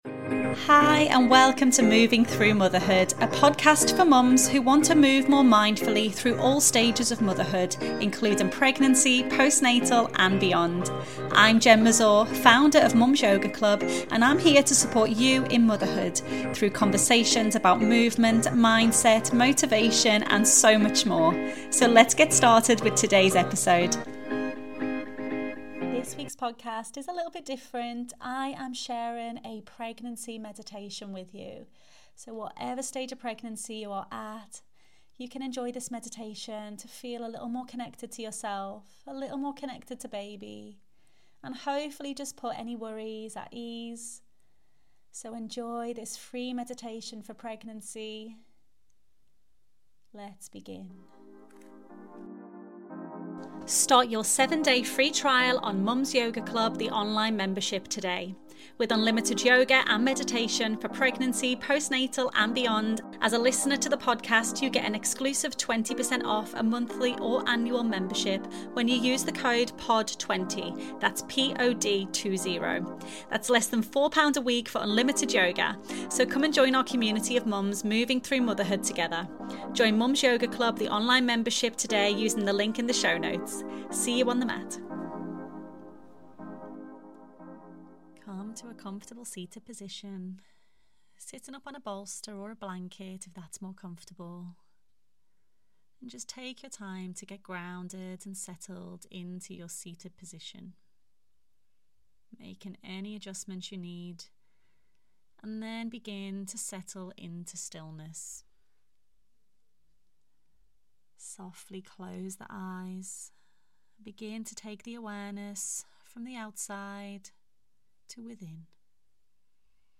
Meditation for Pregnancy